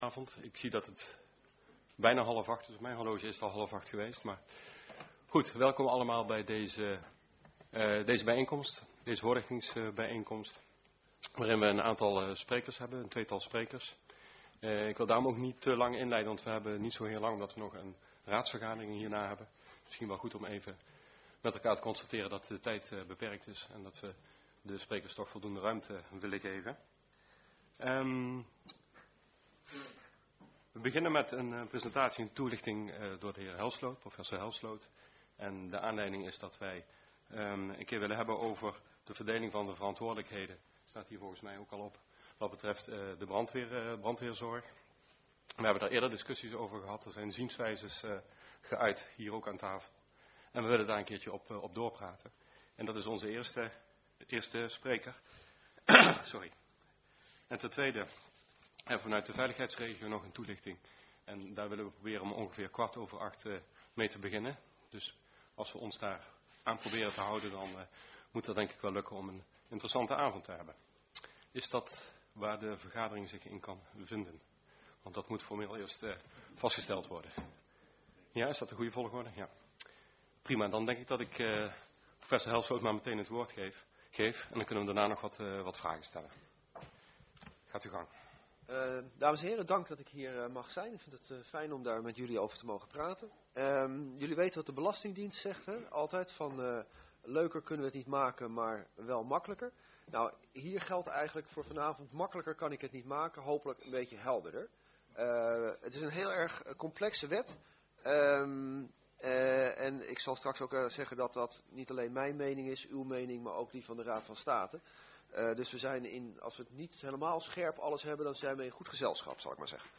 Commissiekamer, gemeentehuis Elst
Informatiebijeenkomst